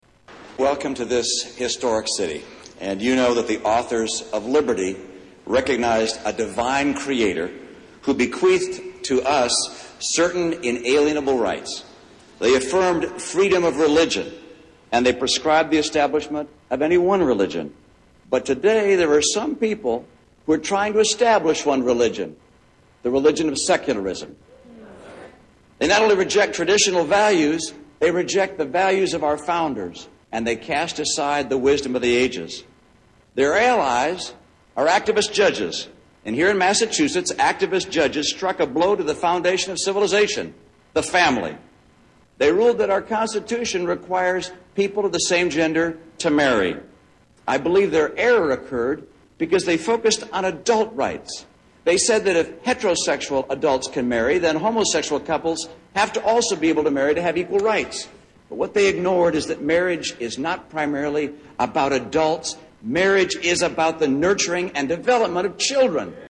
Address on Gay Marriage